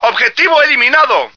flak_m/sounds/male2/est/M2Target.ogg at 602a89cc682bb6abb8a4c4c5544b4943a46f4bd3